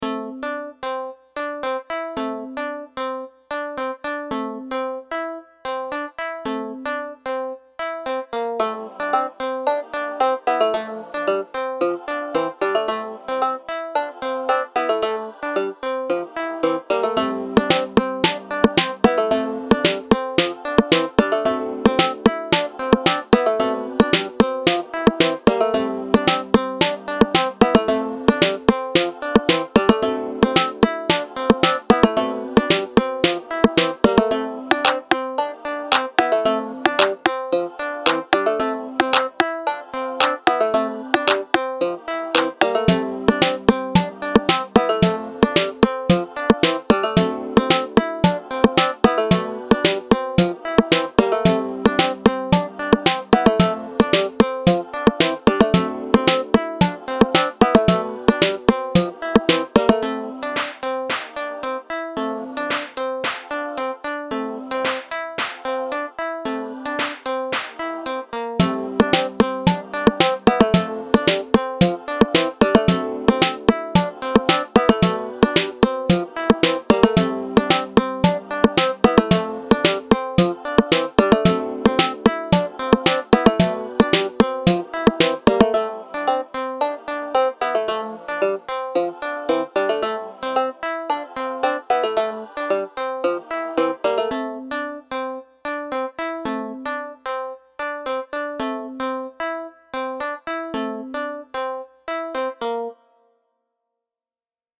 Home > Music > Beats > Bright > Laid Back > Running